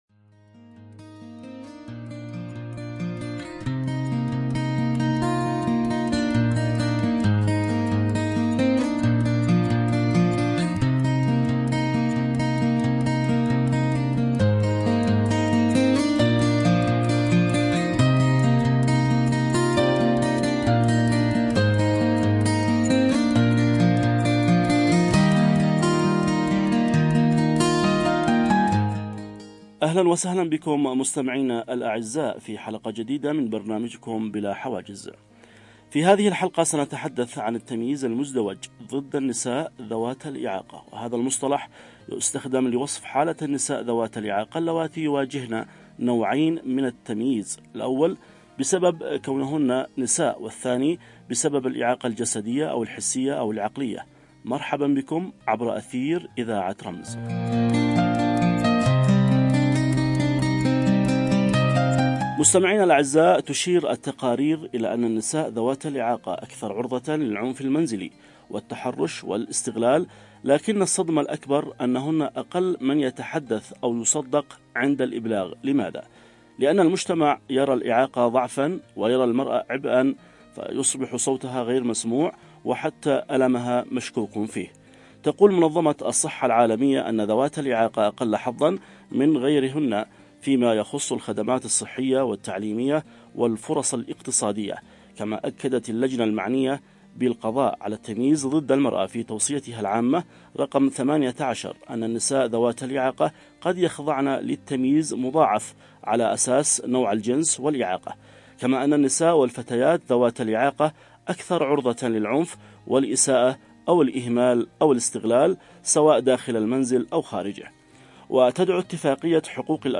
كونوا معنا في نقاش مهم
📅 الموعد: يوم السبت ⏰ الساعة: 11:00 صباحًا 📻 المكان: عبر أثير إذاعة رمز لا تفوّتوا هذه المساحة الحوارية التي تناقش قضية جوهرية فهل تحظى النساء ذوات الإعاقة بفرص متساوية في المجتمع مثل غيرهن؟